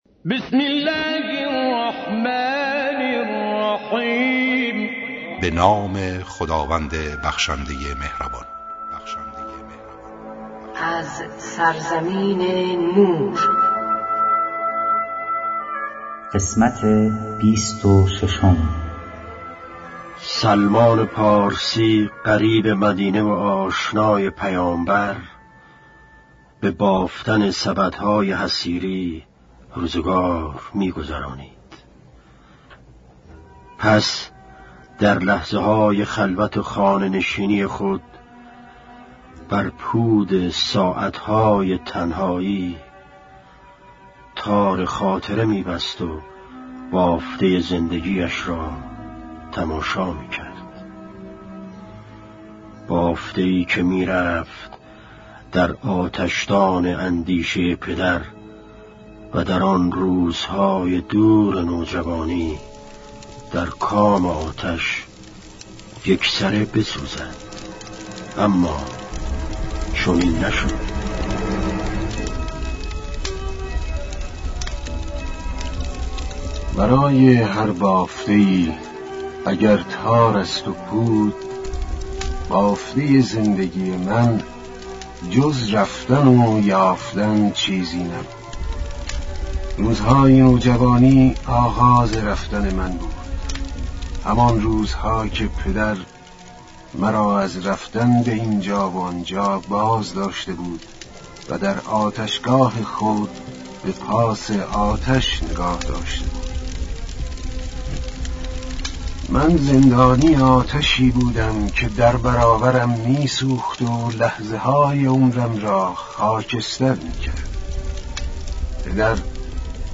با اجرای مشهورترین صداپیشگان، با اصلاح و صداگذاری جدید
کتاب گویا